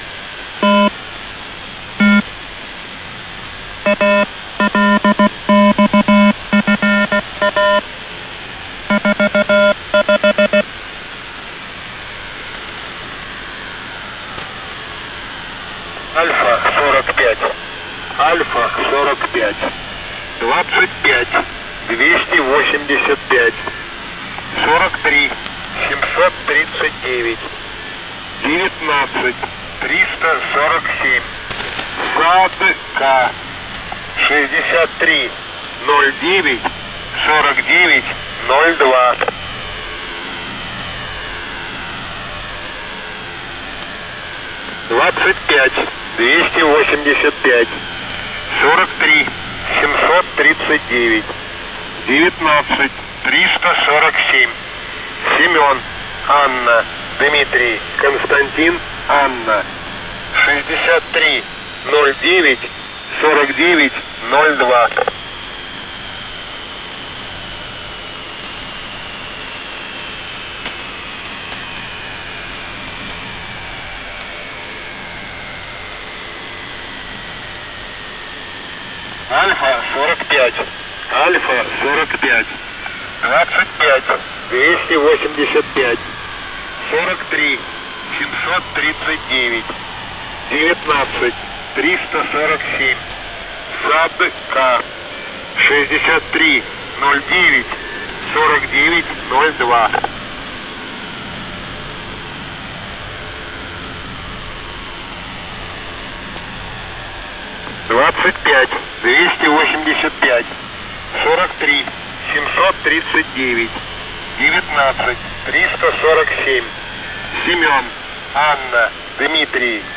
H3E (USB)